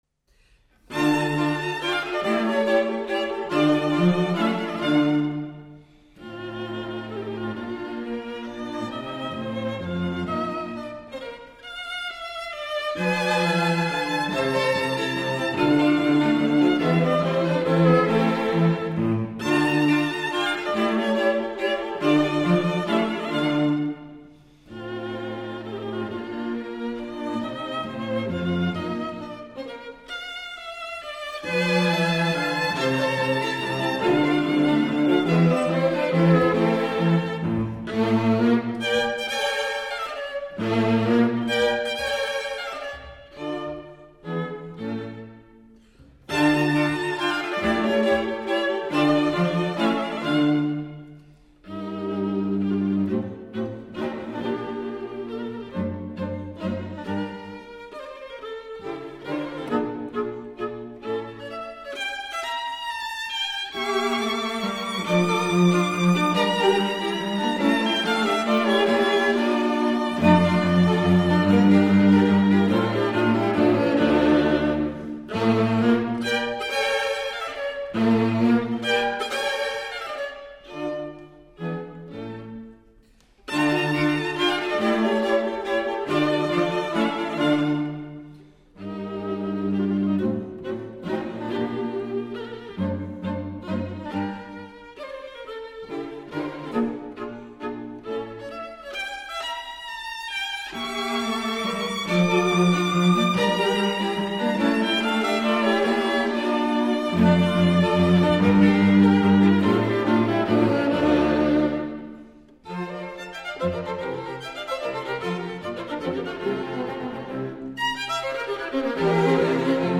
Menuetto